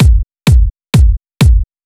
VTDS2 Song Kit 04 Rap Let's Move Kick.wav